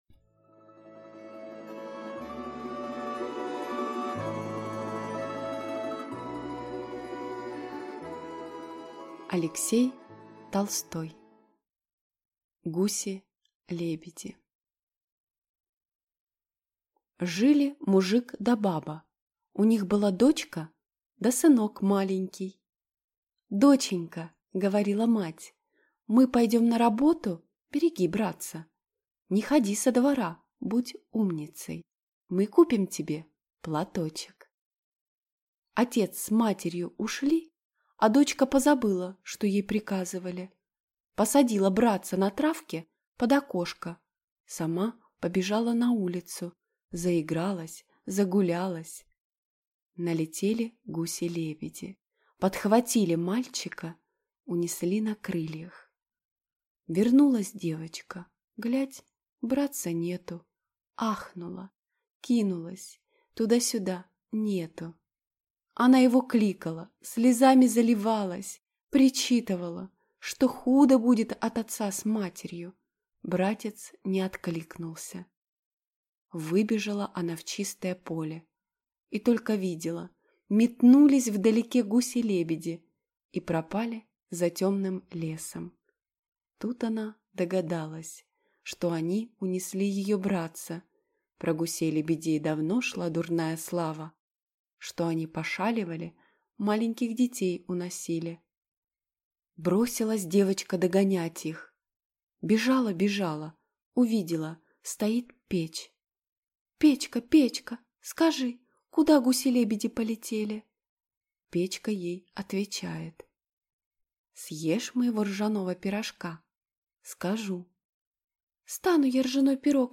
Аудиокнига Гуси-лебеди | Библиотека аудиокниг